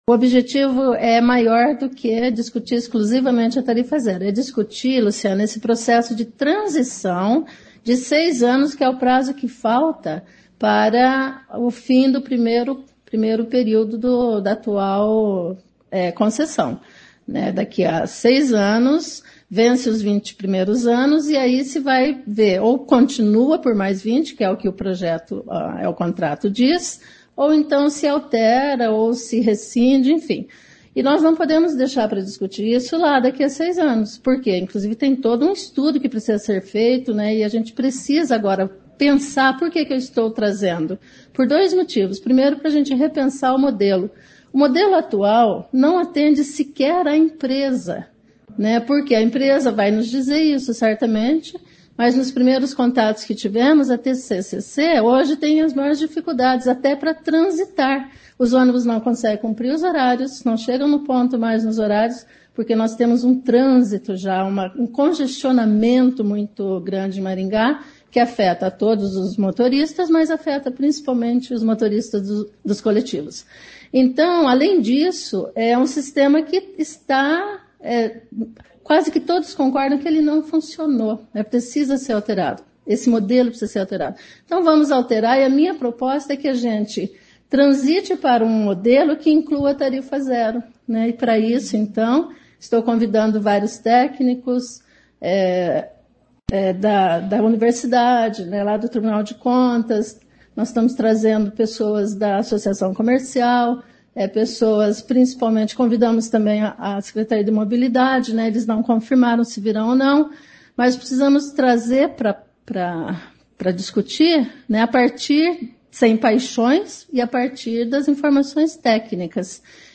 A vereadora Ana Lúcia Rodrigues (PDT) é quem está organizando a audiência.